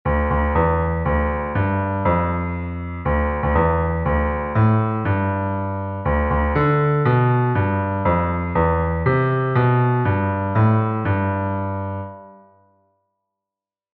der den Flair des Gründungsjahrs der Firma versprühen soll. Das ist ja noch die Pre-Swing Ära, der Jazz war erst auf dem Sprung nach Europa.